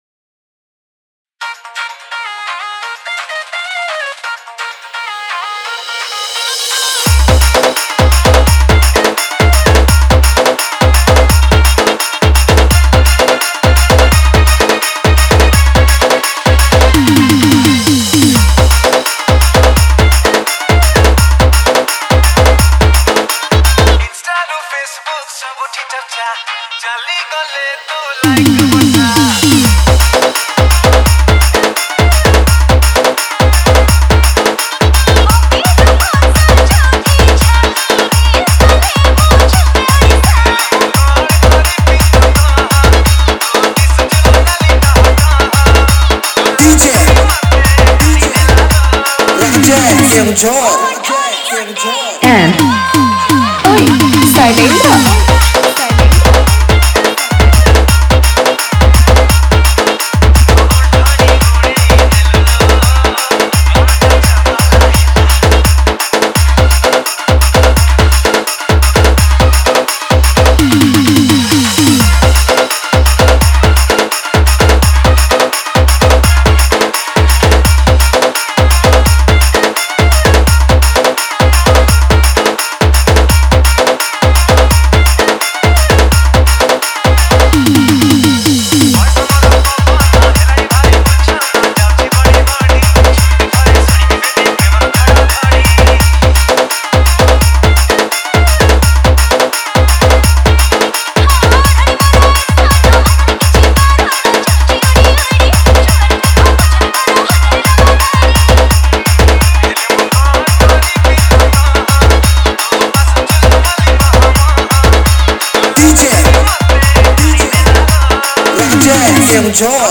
Category:  New Odia Dj Song 2022